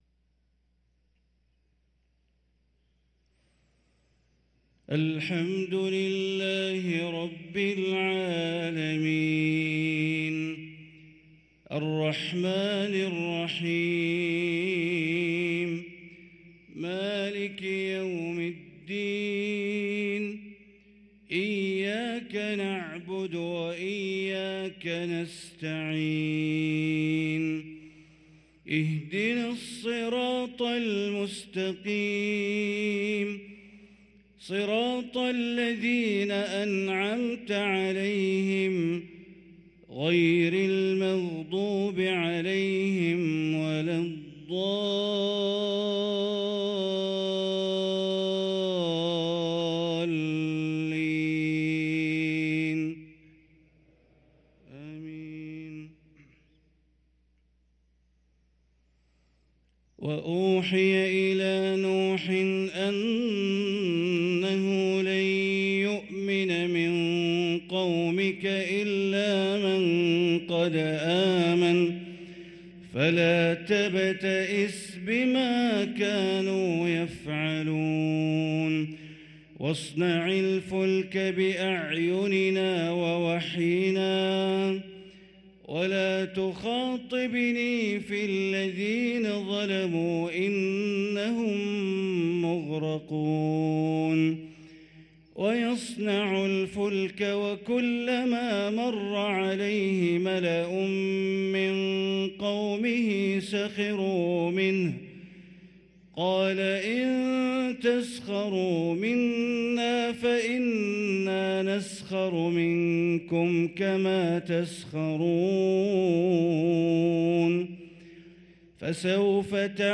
صلاة الفجر للقارئ بندر بليلة 19 جمادي الآخر 1444 هـ
تِلَاوَات الْحَرَمَيْن .